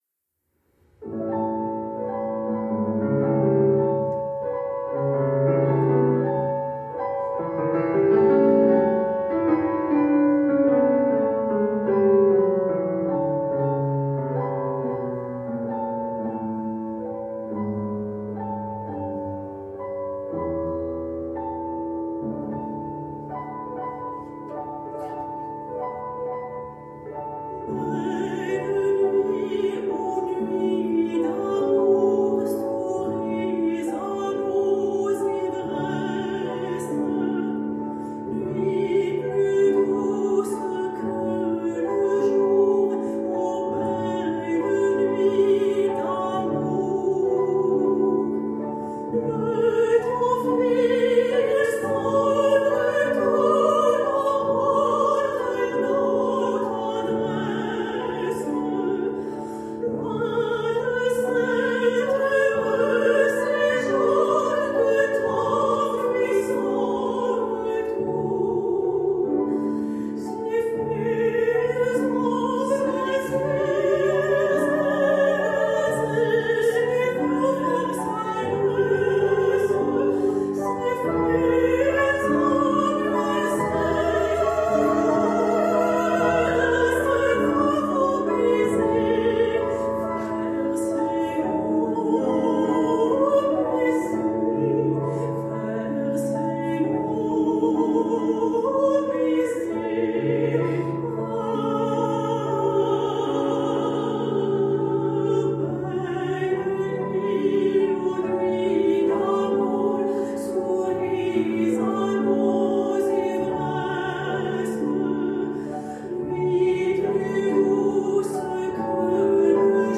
La voix